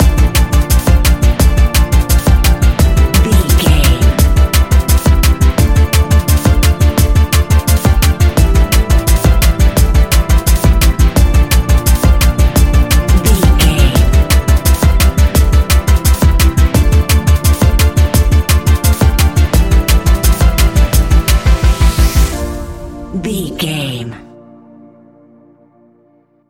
Ionian/Major
A♯
electronic
techno
trance
synthesizer
synthwave